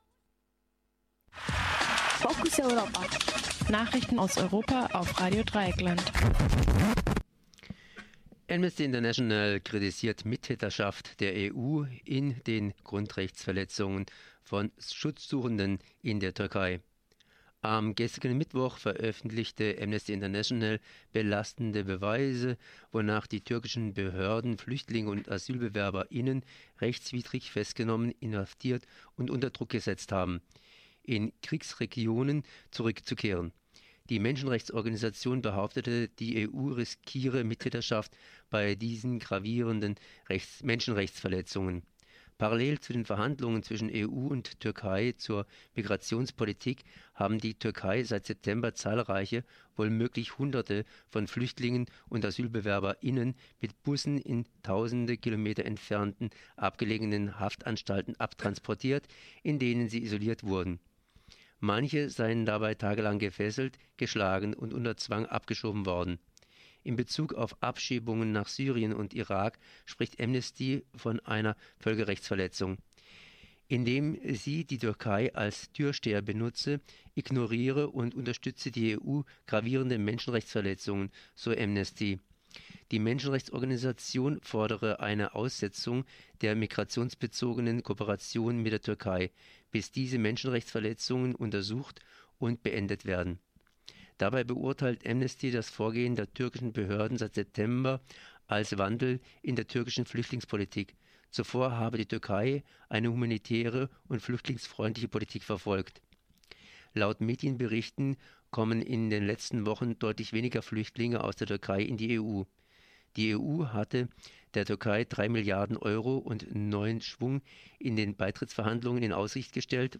Focus Europa Nachrichten am Donnerstag, 17. Dezember 2015 um 9:30